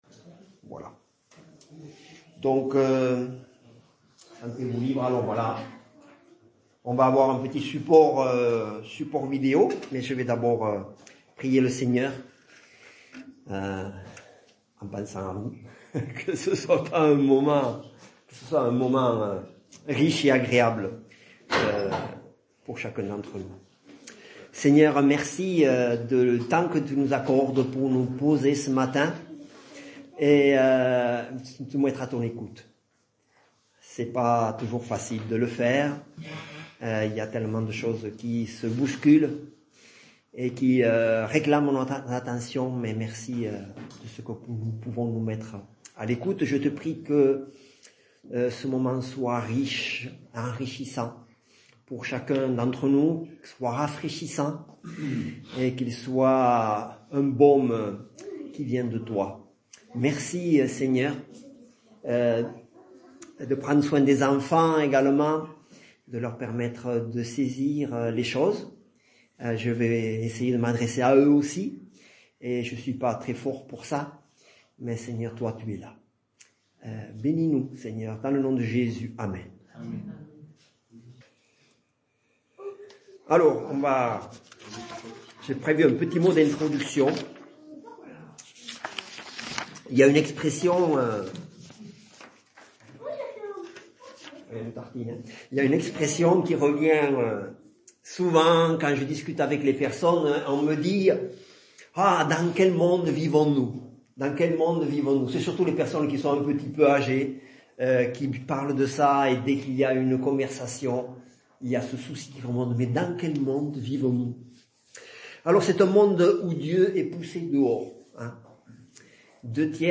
Culte du dimanche 19 janvier 2025 - EPEF